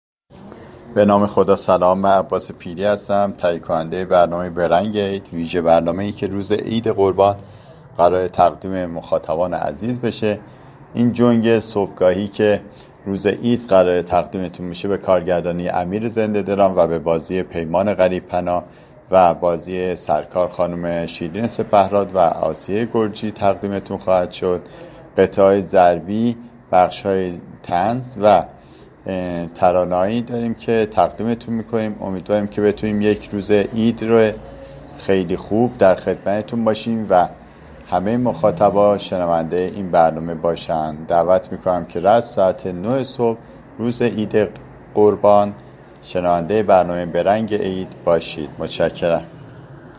ویژه برنامه «به رنگ عید» سلسله برنامه‌هایی است كه در اعیاد پخش می‌شود و این بار به عید سعید قربان اختصاص دارد. «به رنگ عید» با متن‌ها، گزارش وآیتم‌های شاد طنز و نمایش، همراه با شنوندگان شبكه جوان، عید سعید قربان را جشن می‌گیرد.